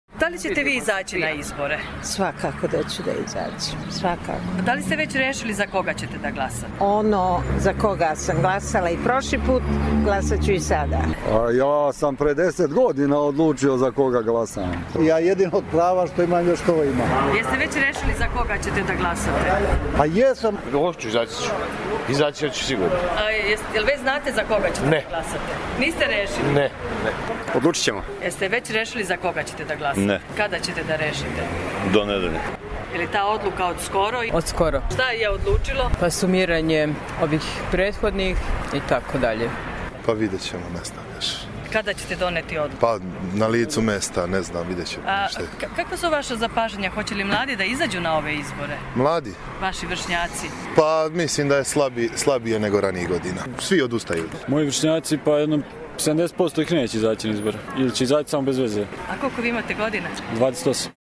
Užičani o glasanju